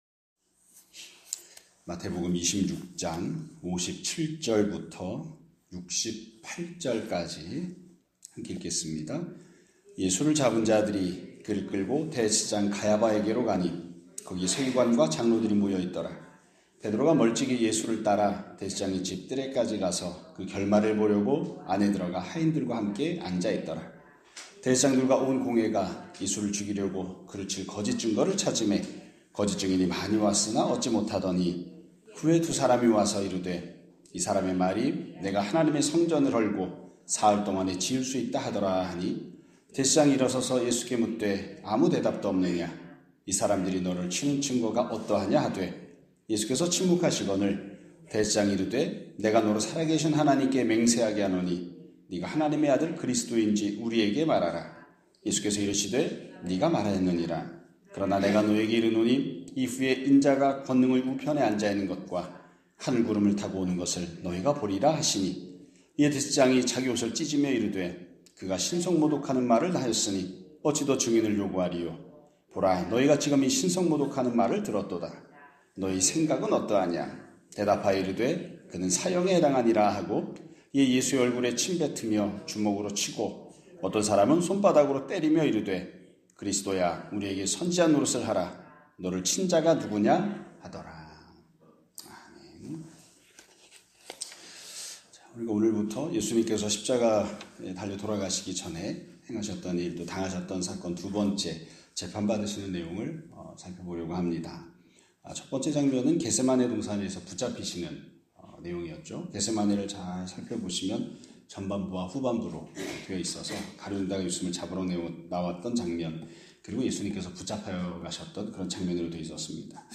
2026년 4월 9일 (목요일) <아침예배> 설교입니다.